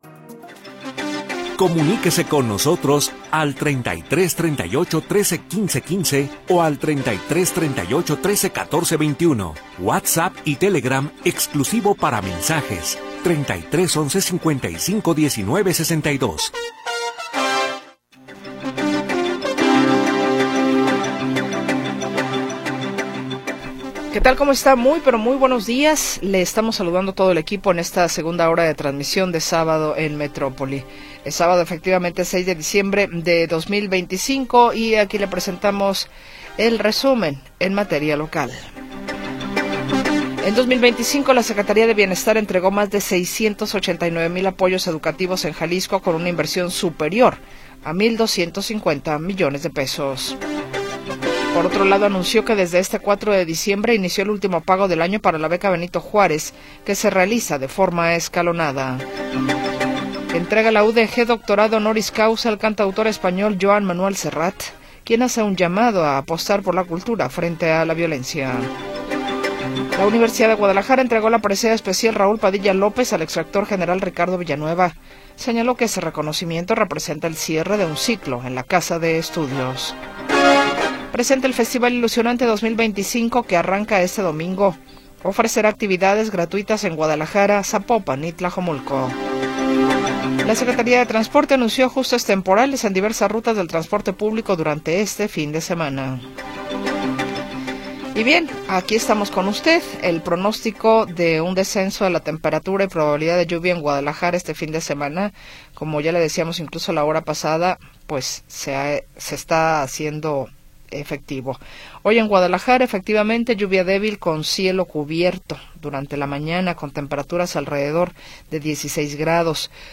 6 de Diciembre de 2025 audio Noticias y entrevistas sobre sucesos del momento